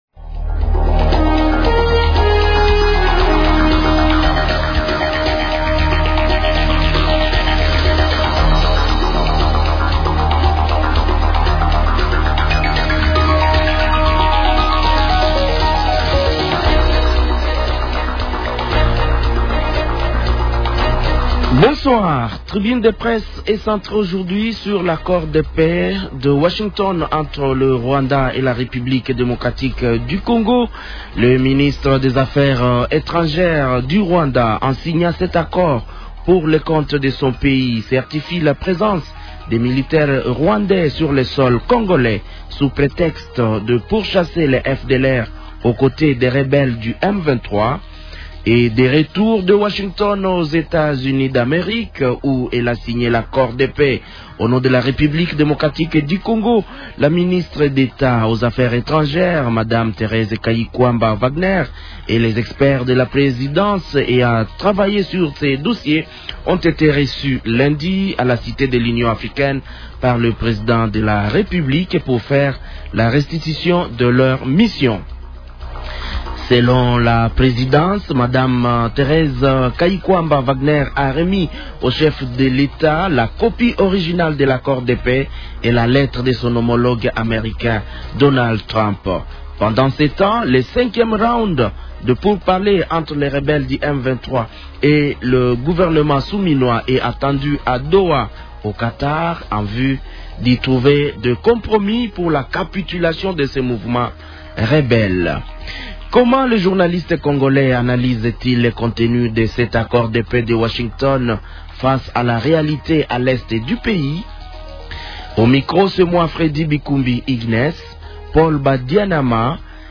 Tribune de la presse : des journalistes commentent l’accord de paix signé entre la RDC et le Rwanda